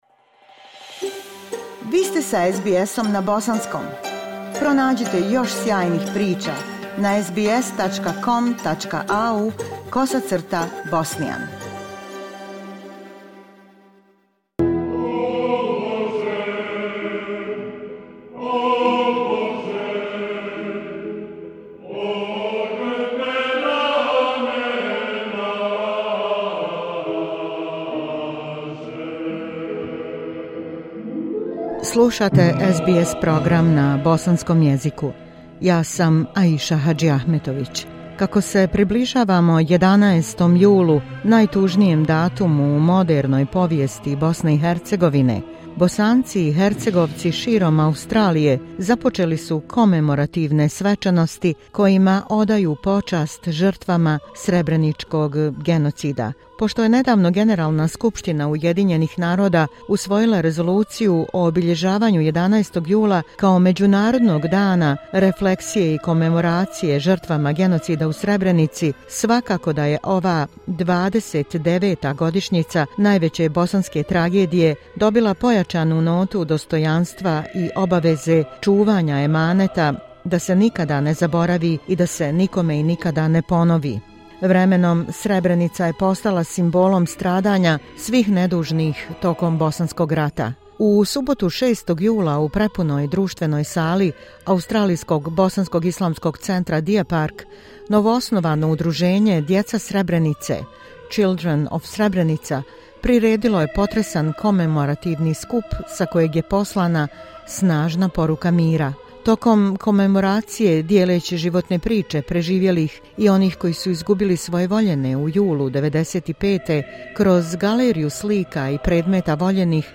Kako idemo u susret 11. julu, najtužnijem datumu u modernoj povijesti BiH, Bosanci i Hercegovci širom Australije započeli su programe komemoracije kojima odaju počast žrtvama srebreničkog genocida. Želeći dati svoj doprinos obilježavanju 11. jula, Međunarodnog dana sjećanja na žrtve srebreničkog genocida, te njegujući na taj način kulturu sjećanja, donosimo reportažu o tome na koji način se održavaju programi komemoracije u Melbourneu, Sydneyu, Perthu i ambasadi BiH u Canberri.